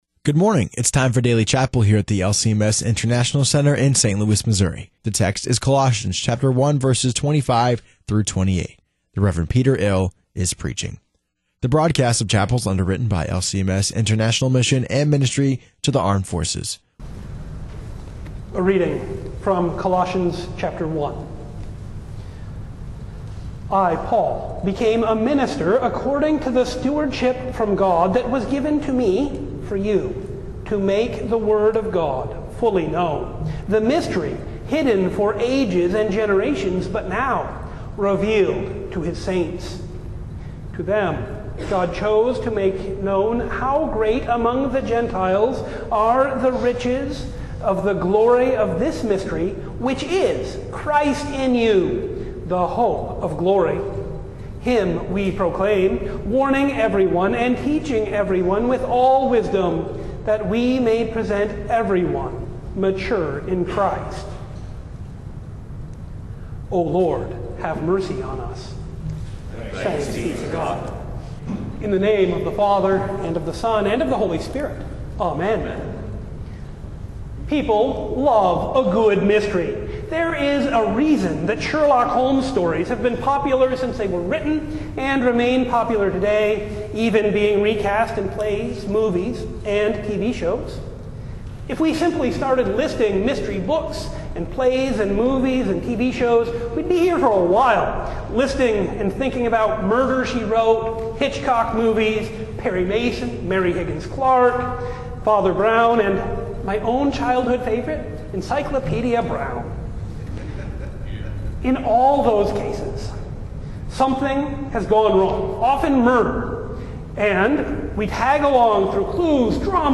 Daily Chapel